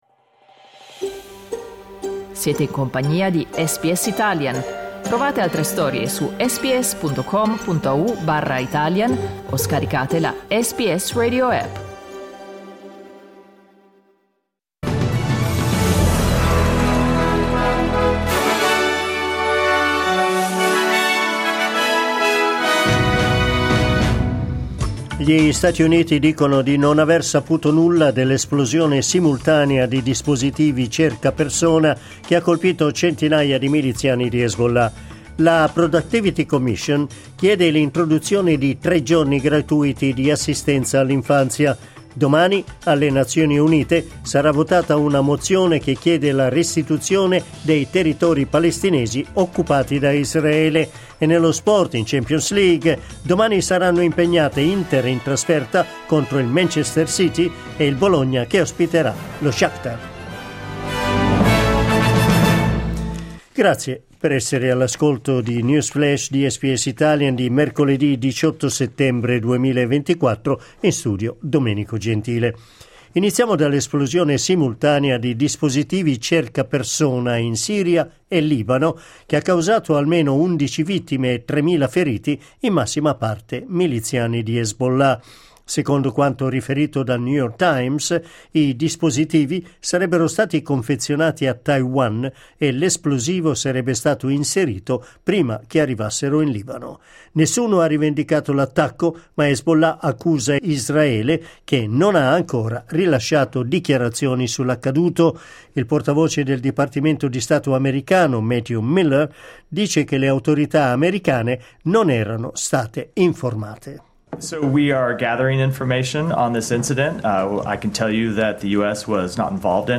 News Flash SBS Italian.